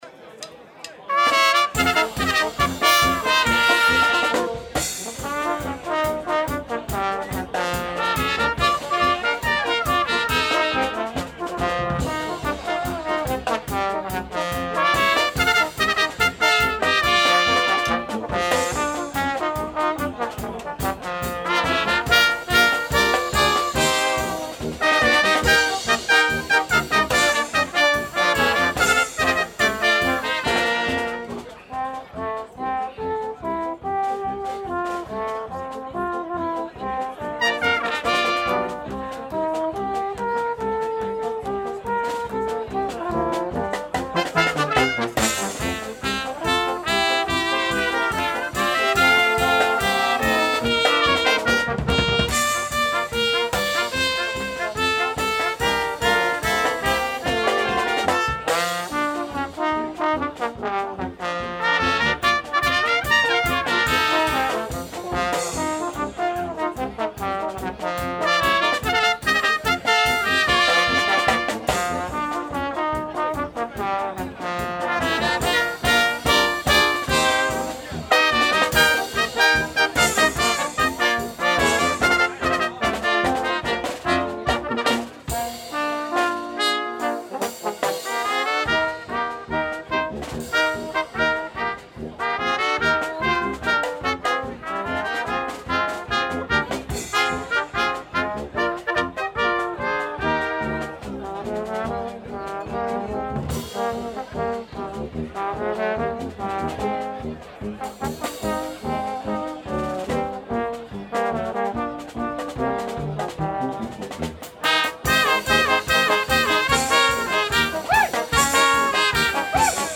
• Balkan/Ompa-ompa
SÅNG